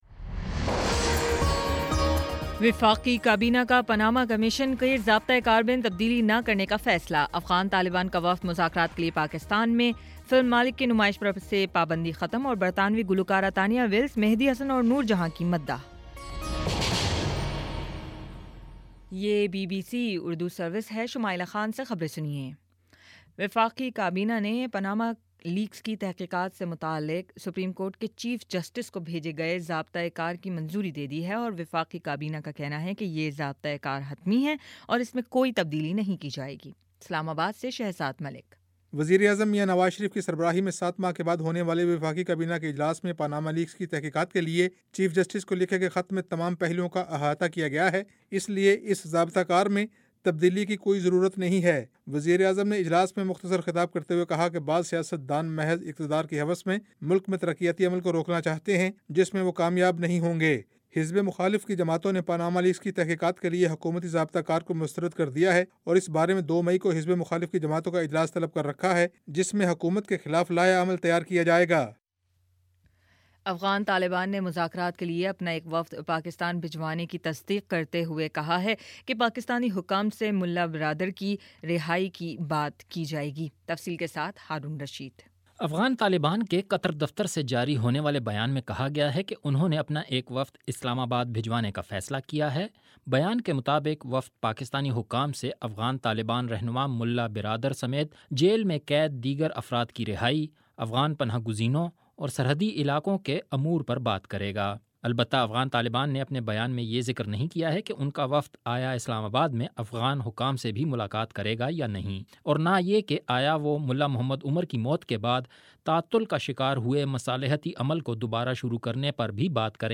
اپریل 27 : شام چھ بجے کا نیوز بُلیٹن